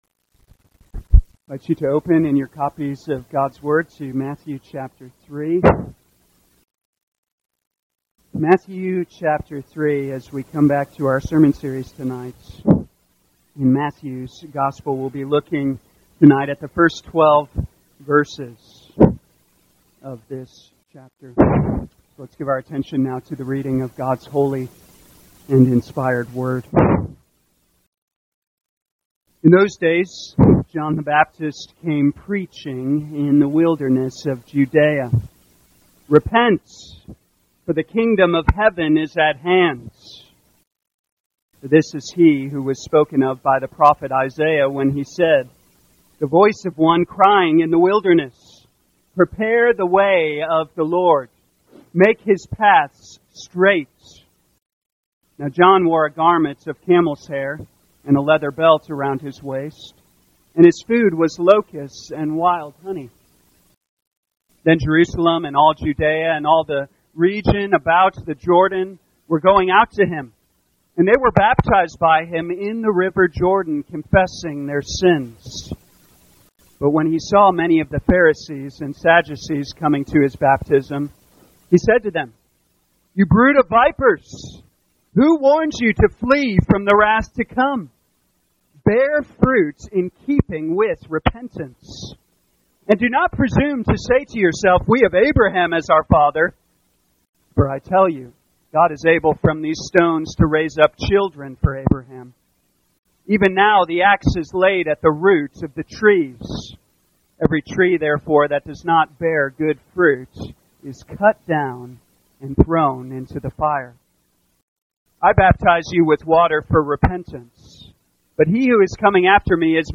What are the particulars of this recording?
2023 Matthew Evening Service Download